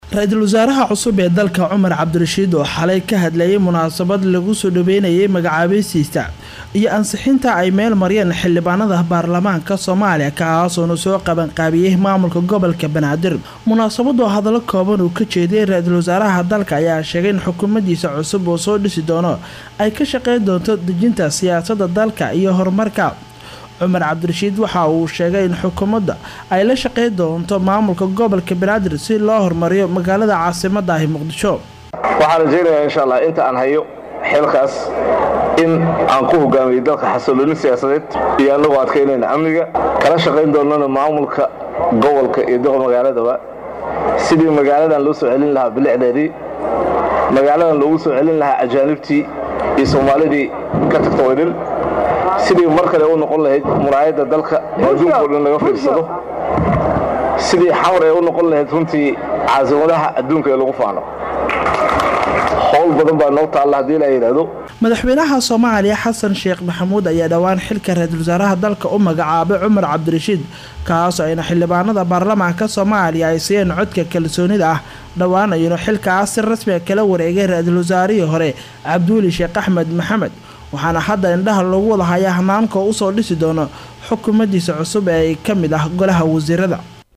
Muqdisho(INO)-Ra’iisul wasaaraha Soomaaliya Cumar C/rashiid Cali,  ayaa xalay ka qeyb galay xaflad ka dhacay magaalada Muqdisho gaar ahaan xaruntii hore ee dad dhigista ee gobolka Banaadir.
Cumar C/rashiid Cali, ayaa balan qaad u sameeyay dadweyne ku sugnaa goobta xaflada ka dhaceysay, isagoo sheegay in xukuumadiisa ay diirada saari doonto arimaha amaanka.